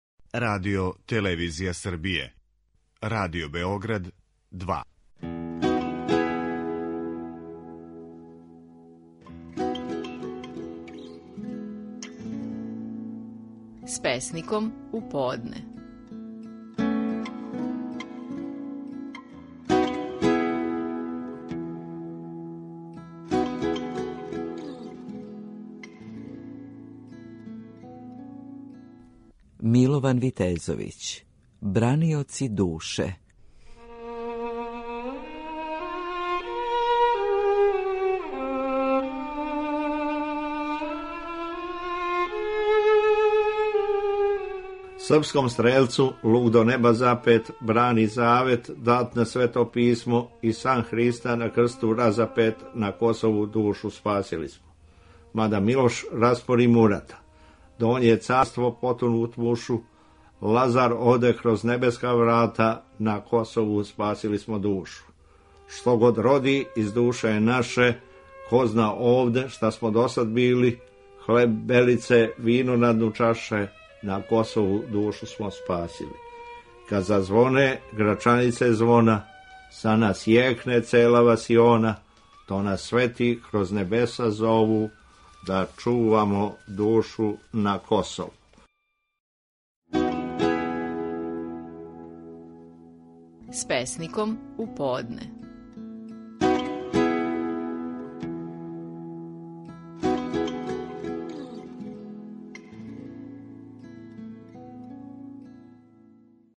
Стихови наших најпознатијих песника, у интерпретацији аутора.
Милован Витезовић говори песму „Браниоци душе".